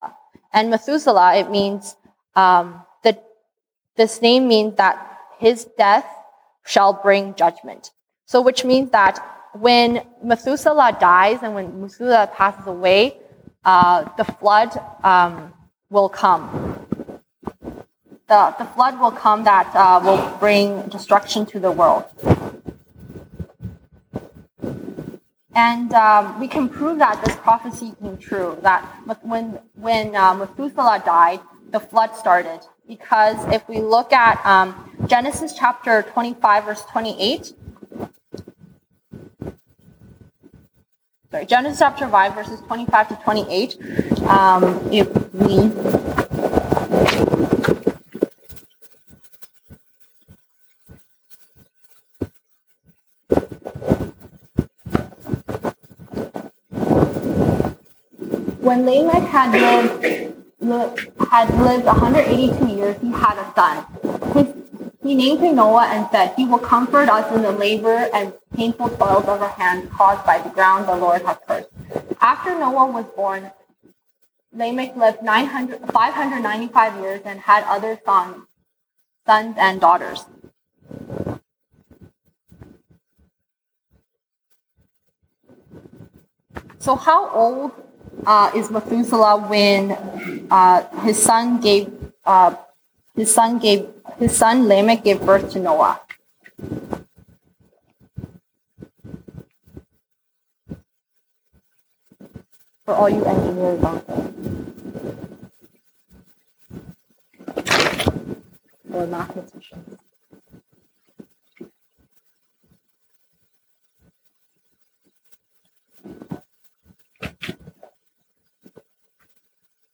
西堂證道 (英語) Sunday Service English: God’s Past and Future Judgement